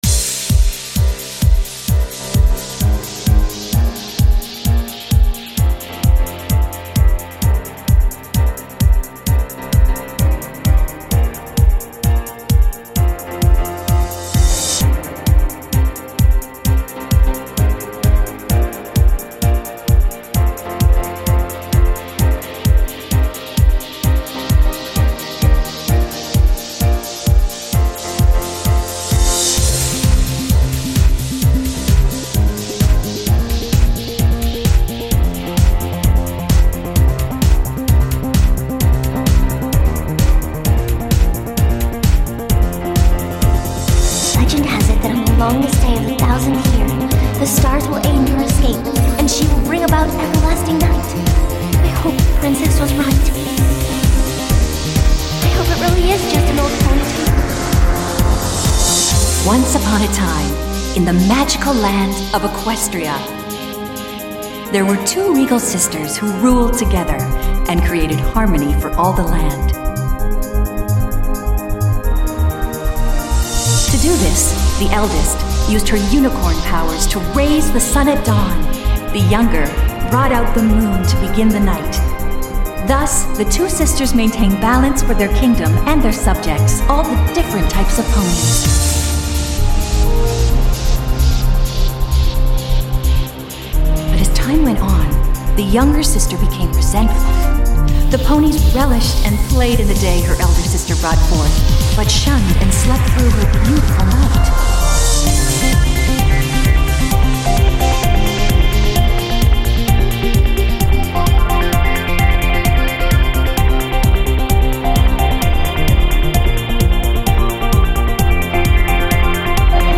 electronic / dance remix